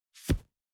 435物を置く,バックを置く,荷物を置く,トン,コト,ドサ,ストン,ガチャ,ポン,タン,スッ,ゴト,カチャ,
効果音室内物を置く